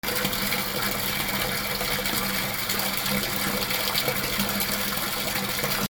水音 水が水面に落ちる音 じょぼぼぼ 浴槽に水を張る音
『ダダダ』